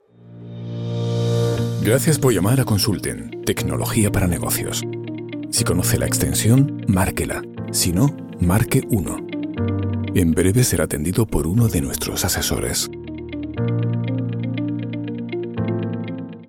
IVR
Ich kann mit englischem und andalusischem Akzent sprechen.
BaritonTiefNiedrig